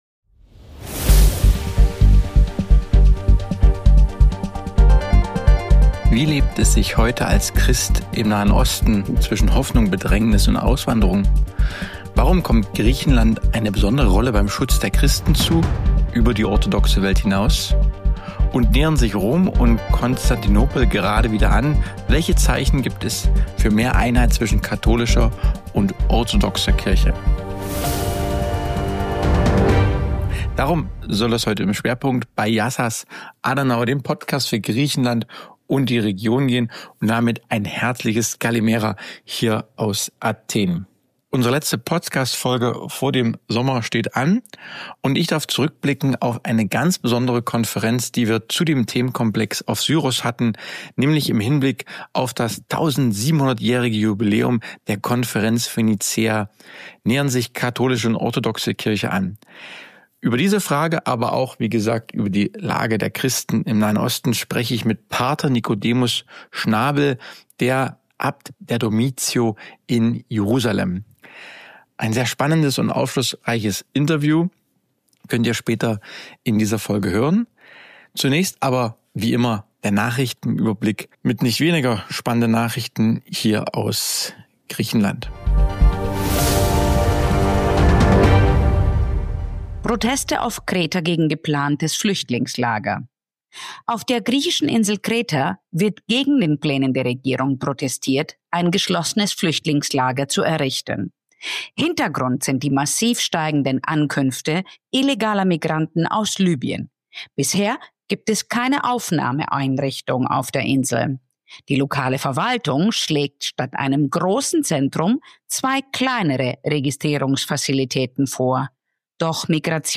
Ein starkes Gespräch über Glauben, Mut und den Auftrag, dazubleiben – gerade wenn es schwierig wird.